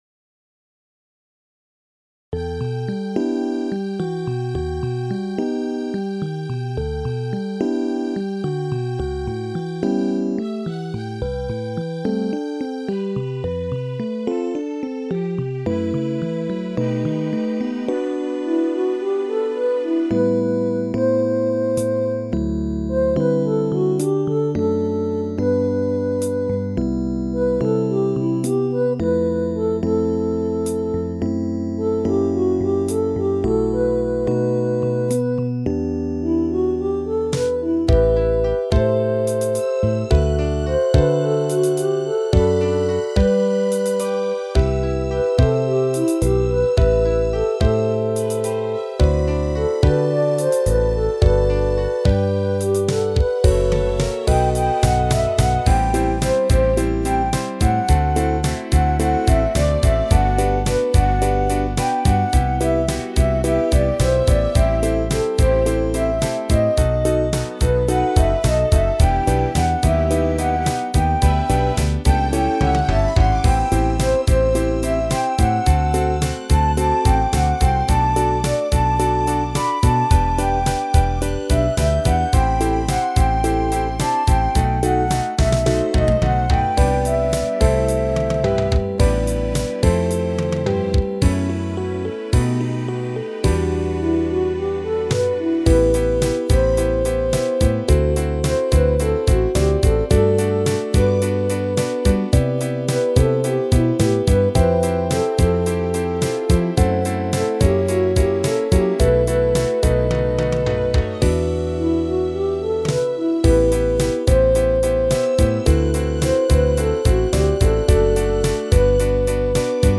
とにかくガチャガチャは嫌いなのでシンプルに。